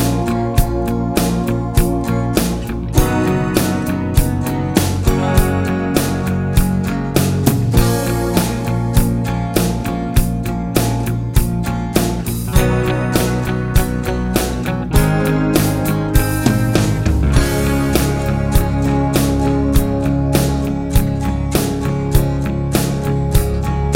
Four Semitones Down Rock 6:02 Buy £1.50